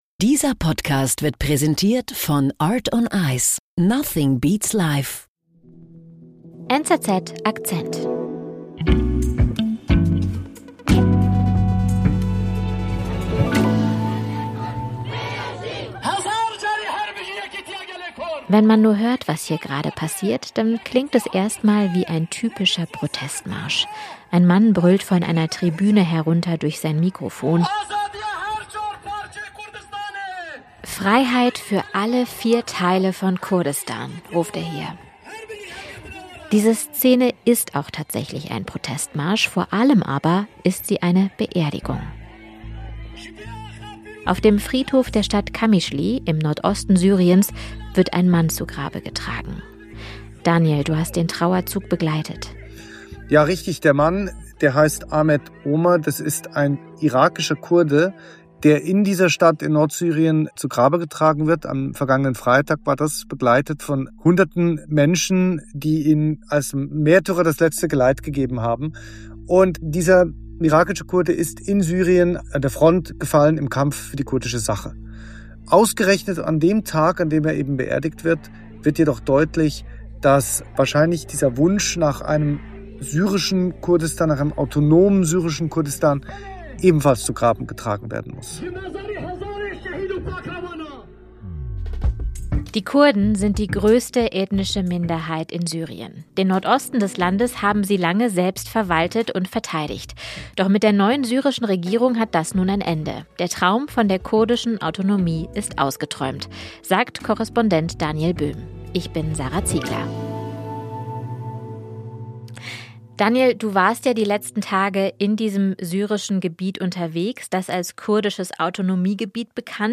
Beschreibung vor 2 Monaten Was wie ein lauter Protestmarsch klingt, ist in Wahrheit eine Beerdigung.